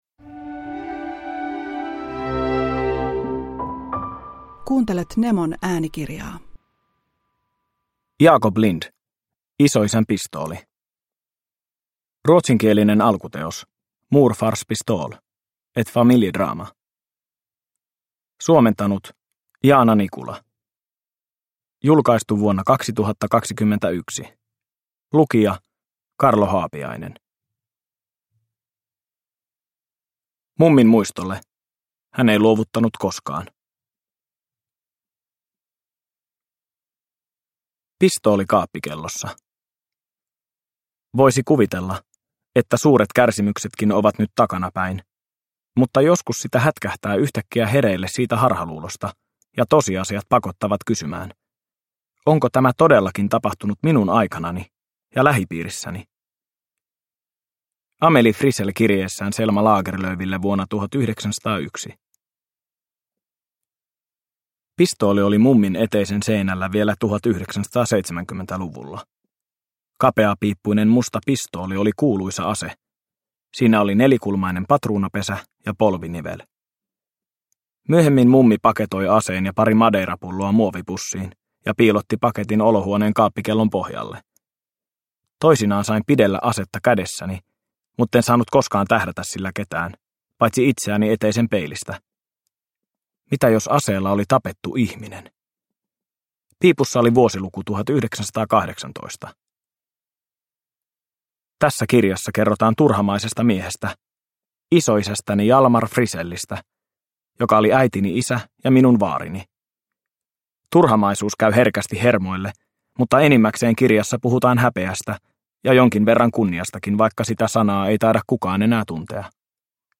Isoisän pistooli – Ljudbok – Laddas ner